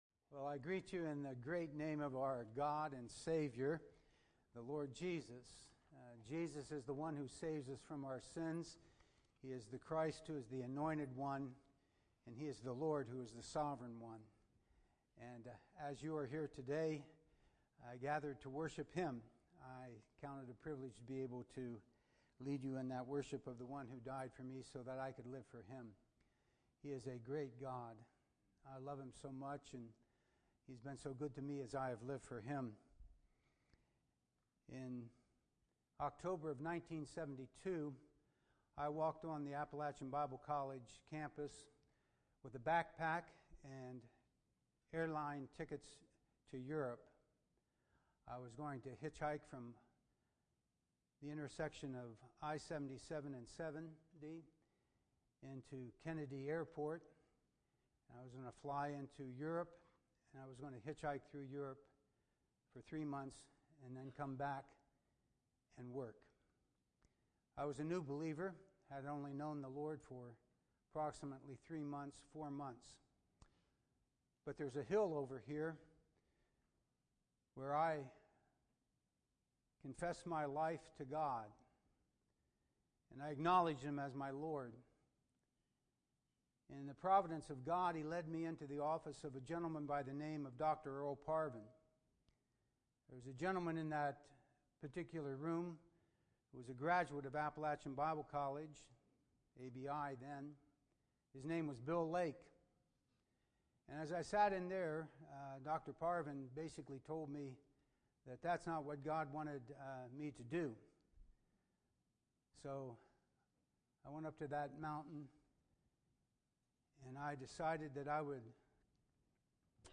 Chapel Message